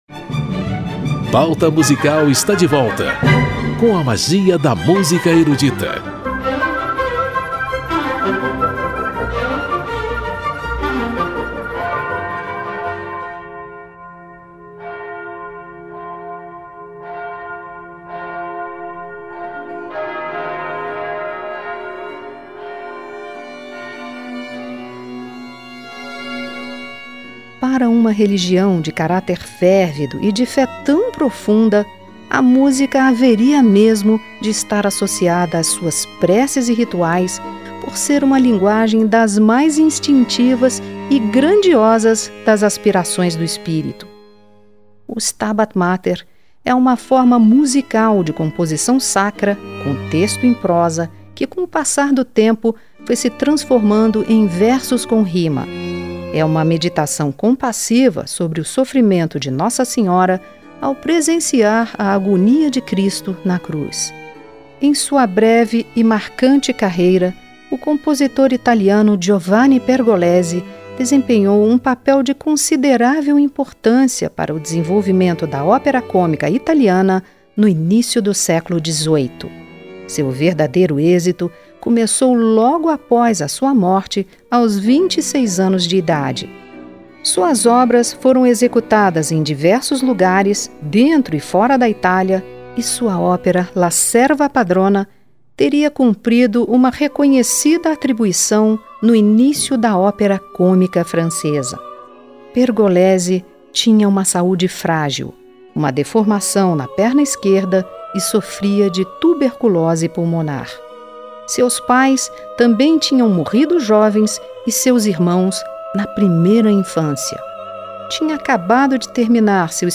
Afinada com o espírito cristão das celebrações da Semana Santa, uma seleção de excertos do Stabat Mater de Pergolesi e do Stabat Mater de Scarlatti.